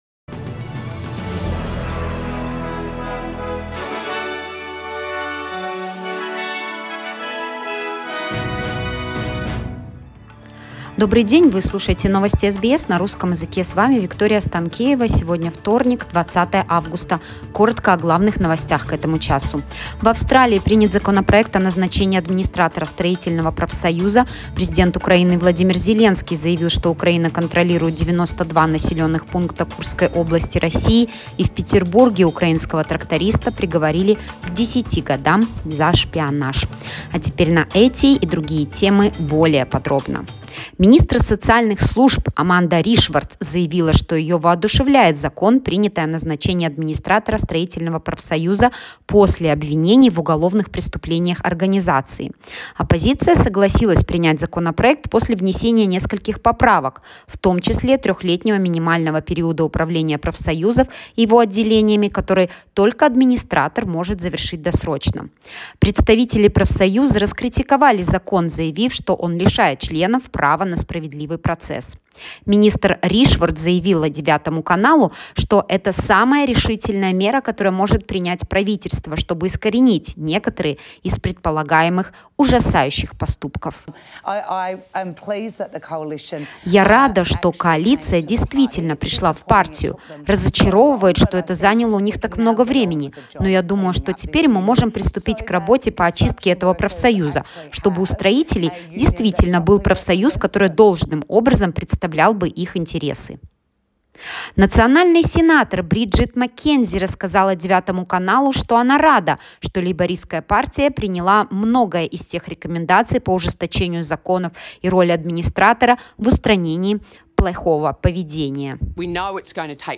Новости SBS на русском языке — 20.08.2024